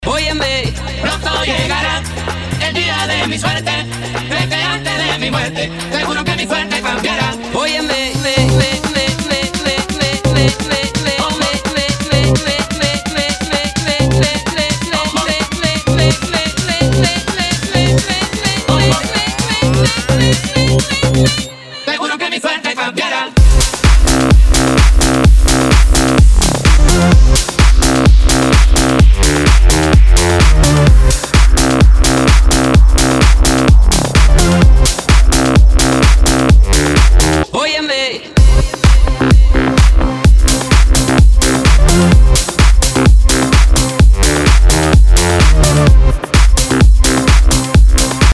His style is unique and electrifying.